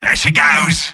Robot-filtered lines from MvM.